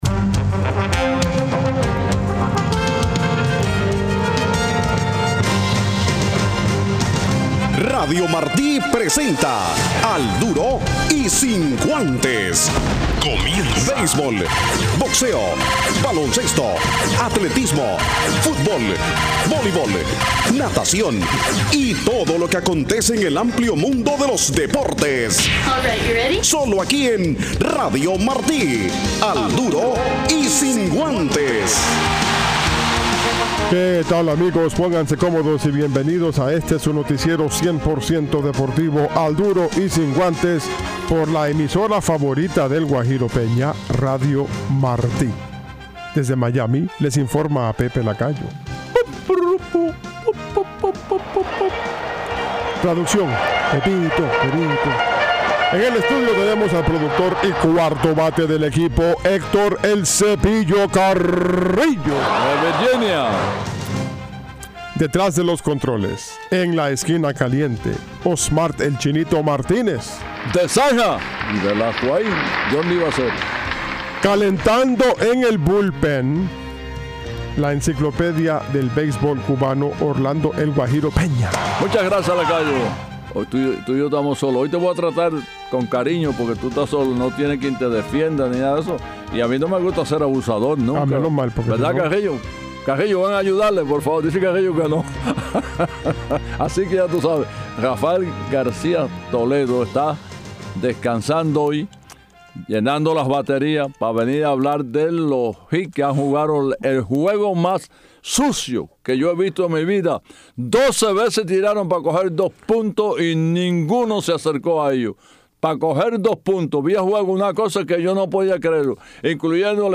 Al Duro del miercoles con una llamada a nuestra embajada en Cuba, la Peña de Peña en Florida, Camaguey